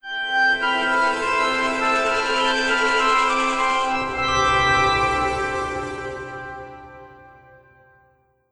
Windows Vista Logo Animation Sound.wav